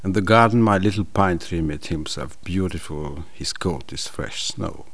here to listen to an example of speech recorded with a dynamic microphone, and click here to listen to an example recorded with a condenser microphone.
FIGURE 5.3. Dynamic (Sure SM-57) and condenser (right?KG 414-EB) microphones.
MICSM57.AIF